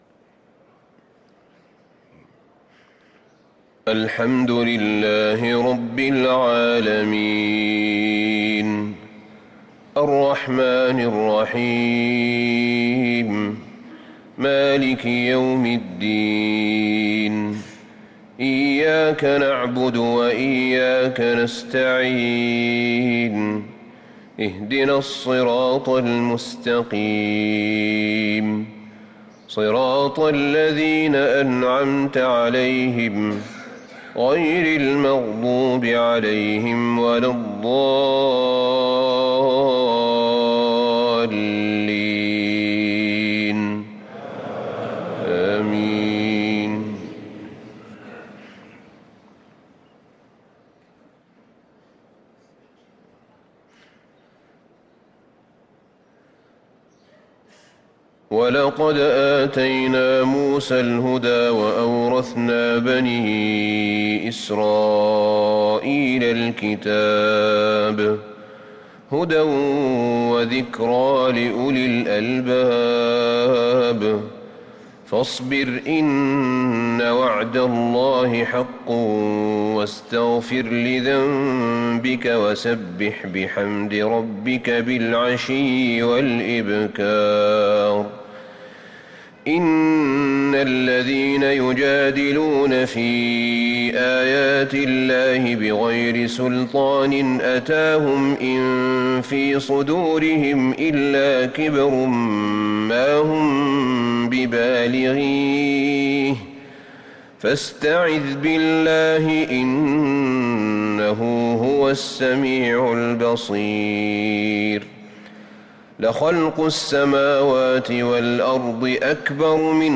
صلاة الفجر للقارئ أحمد بن طالب حميد 27 صفر 1445 هـ